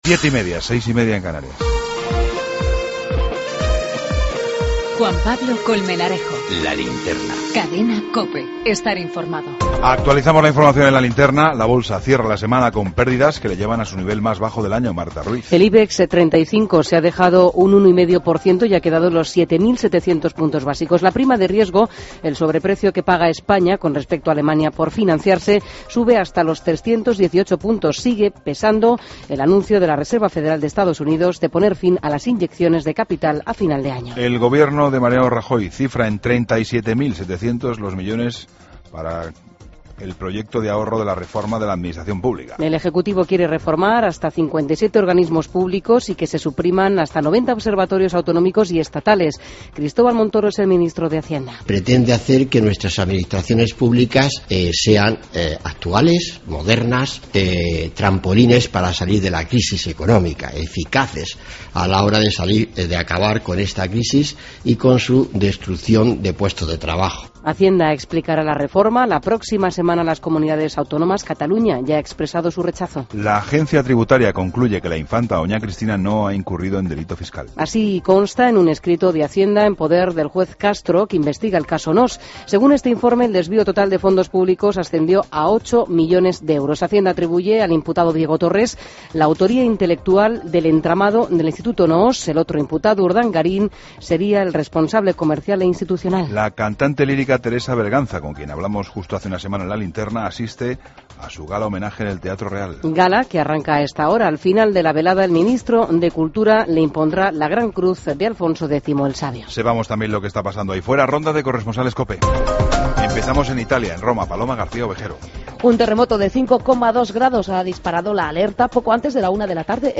Ronda de corresponsales.
Entrevista a Julios Flores, teniente alcalde de La Coruña.